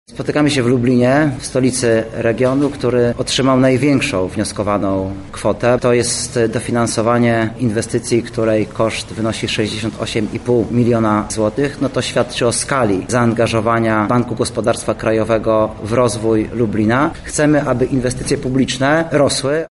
Kolejna edycja programu daje możliwość samorządom na realizacje ich ambicji inwestycyjnych – mówi Artur Soboń, wiceminister finansów.